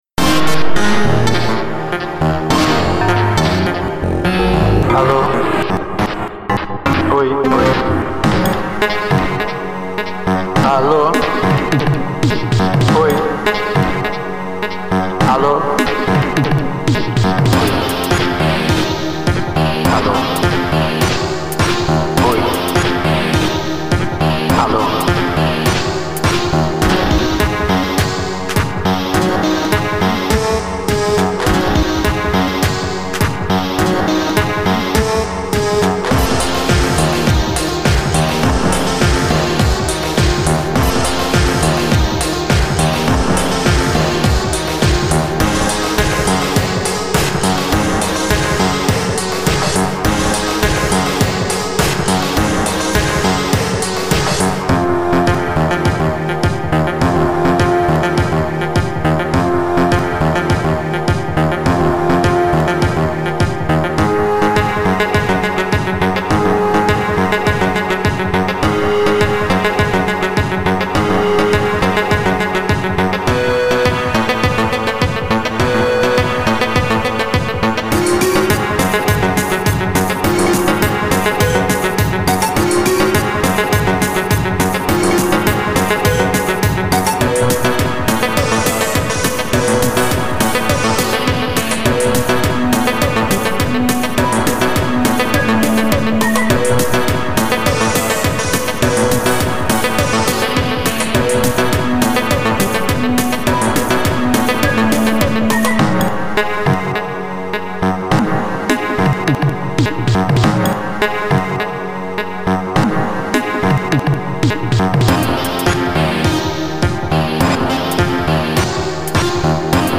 Instrumental eletronico manual